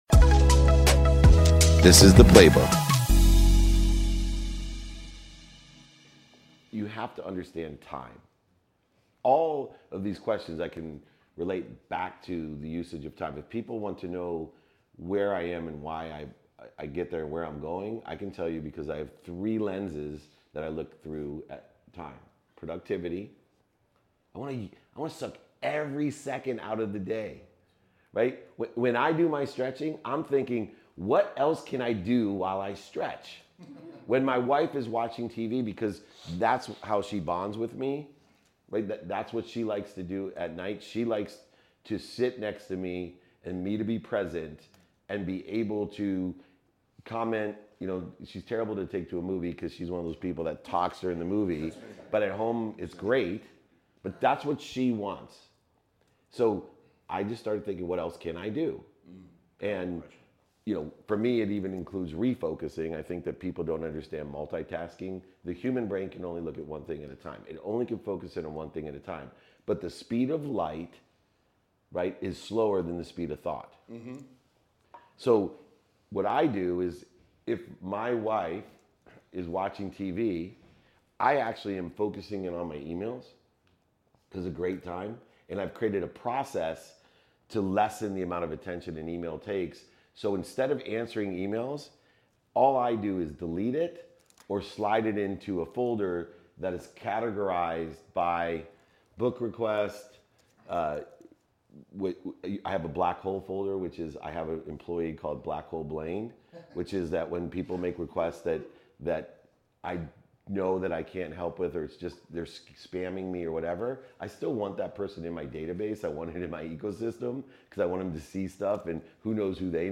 mastermind at AlignCon 2023